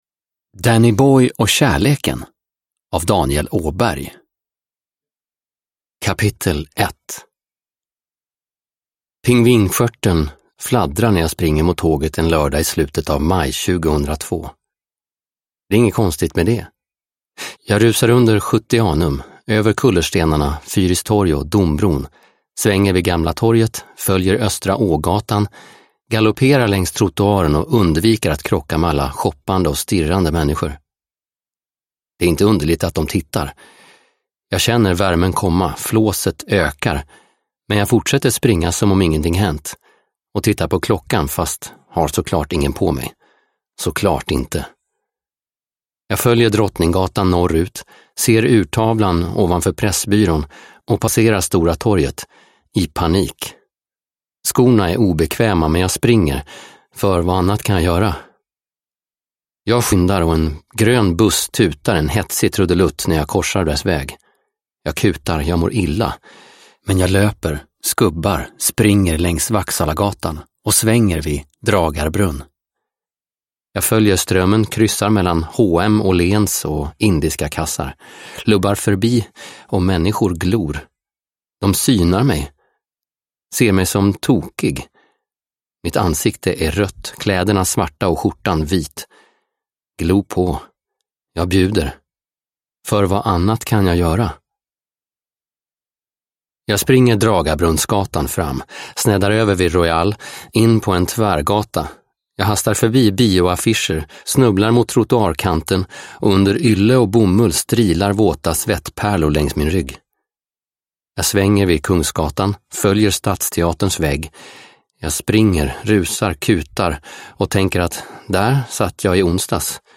Dannyboy & kärleken – Ljudbok – Laddas ner